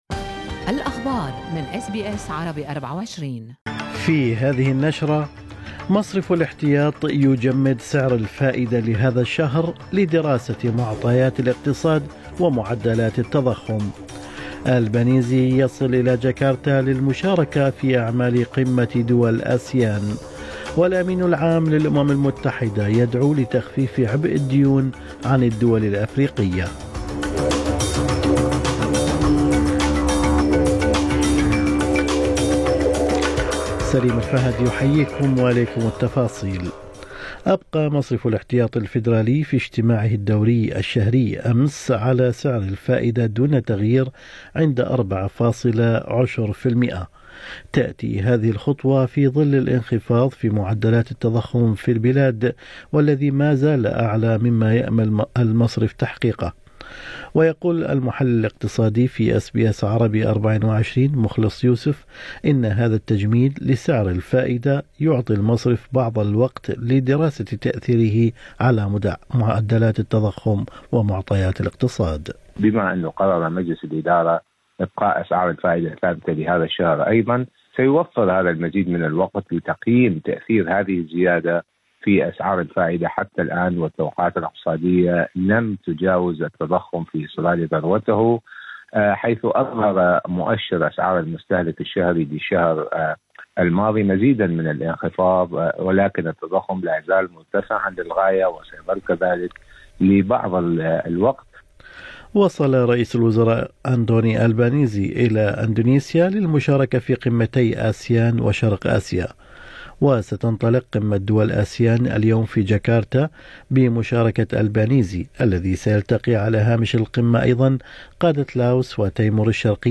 نشرة أخبار الصباح 6/9/2023